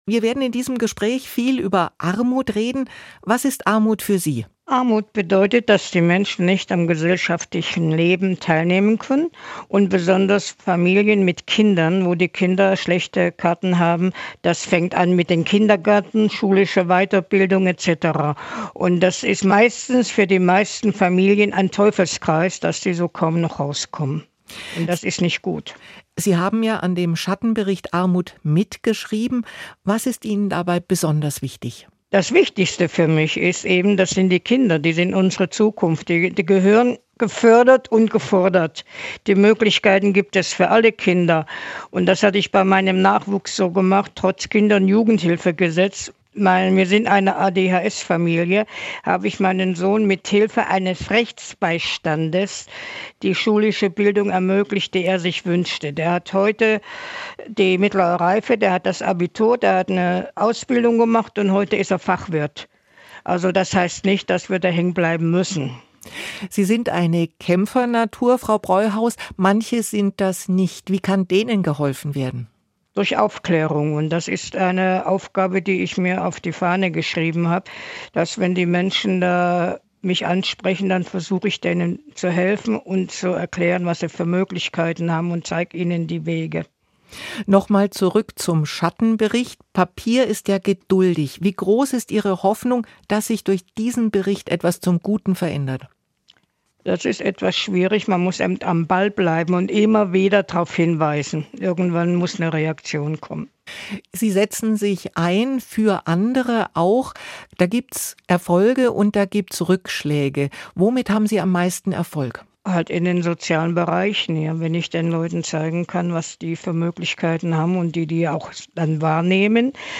3. Radio